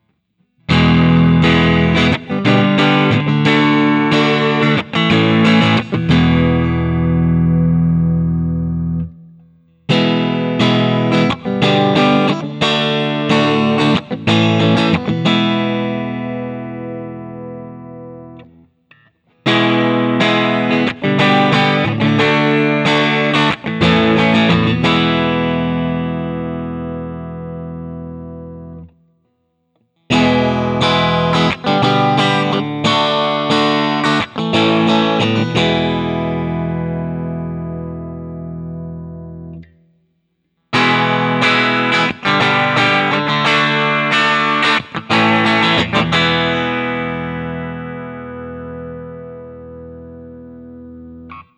Plugged in it sounds like a Strat.
Open Chords #2
For the first five recordings I had the compressor on and I think it made everything sound a bit flat and harsh because as soon as I turned it off I liked it better, but I’m too lazy to re-record.
For most of the recordings I play my usual test chords through all five of the pickup settings in the following order: